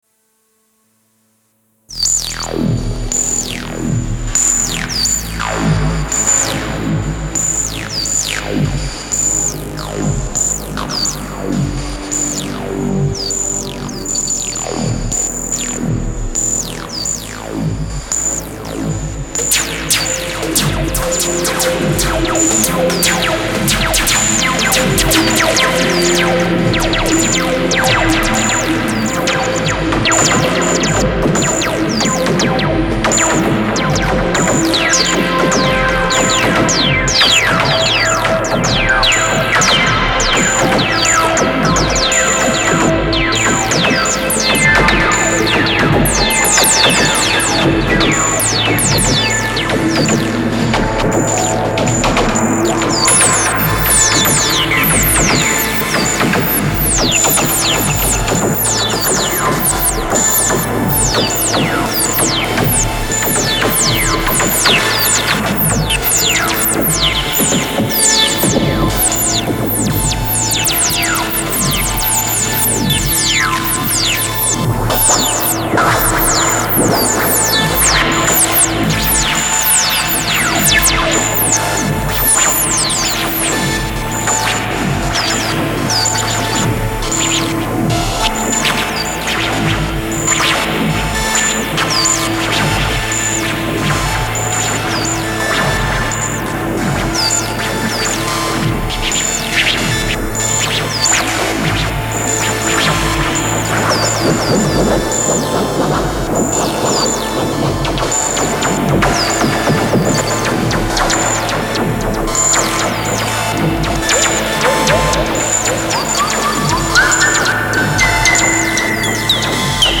I record using a hardware tool.